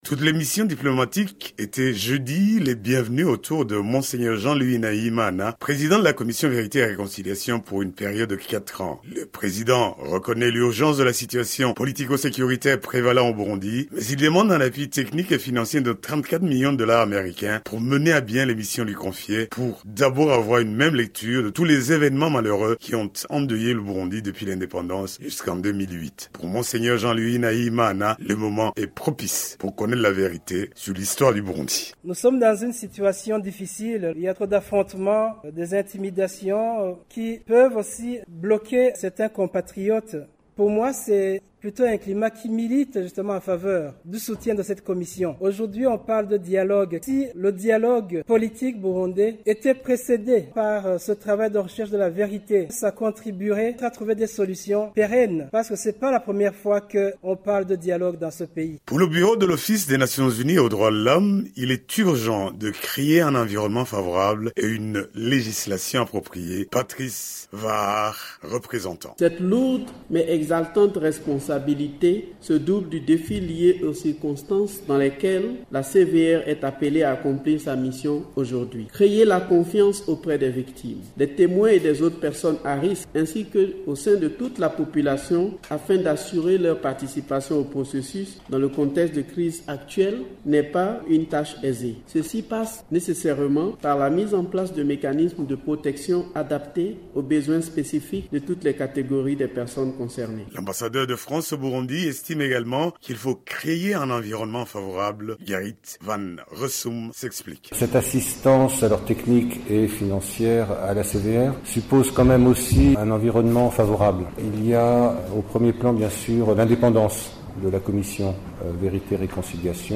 Reportage
à Bujumbura